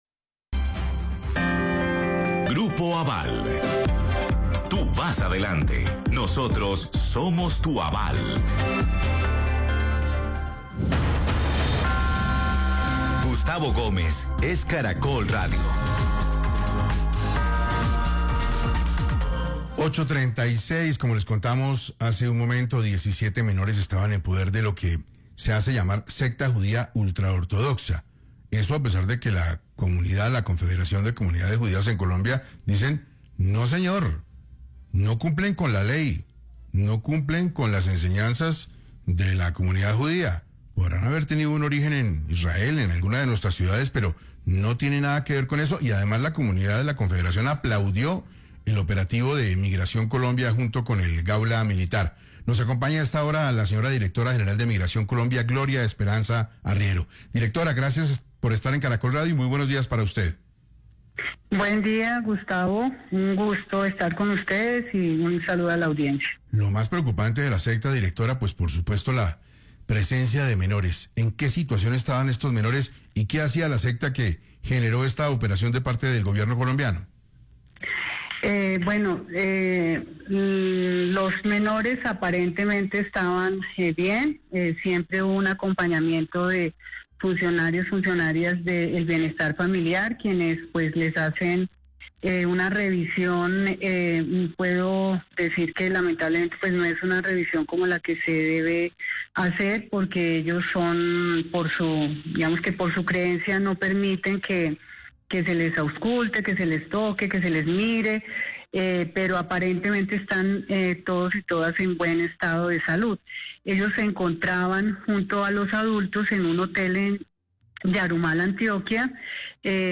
Gloria Esperanza Arriero explicó en 6AM de Caracol Radio cómo operaba la secta y por qué los niños tenían circular amarilla de Interpol